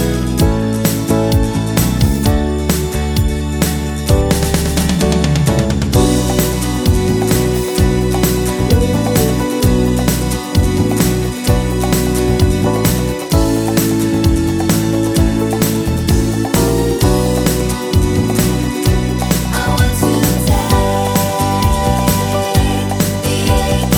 No Backing Vocals Crooners 3:47 Buy £1.50